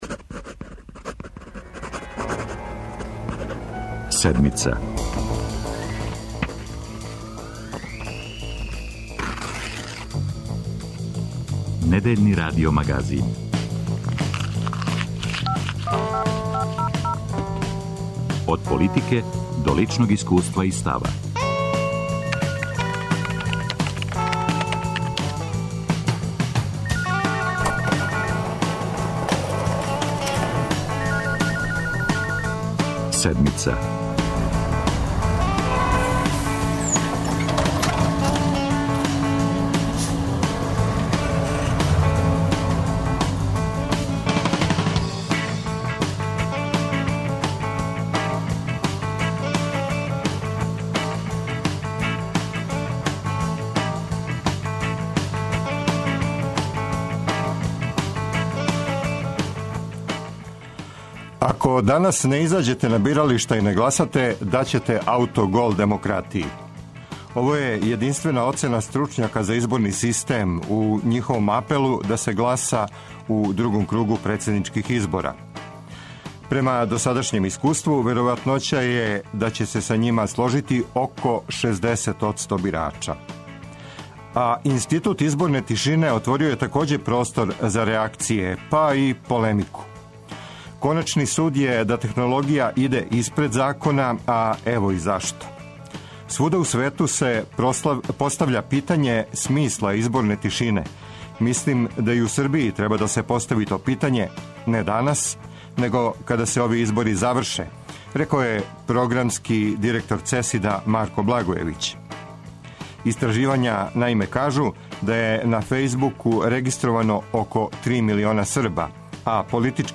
Еврозона и евро - ове две речи зује у ушима Брисела, Берлина, Париза, Атине. Куда иде Европа, тема је трибине Седмице.